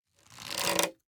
Minecraft Version Minecraft Version 25w18a Latest Release | Latest Snapshot 25w18a / assets / minecraft / sounds / item / crossbow / loading_middle3.ogg Compare With Compare With Latest Release | Latest Snapshot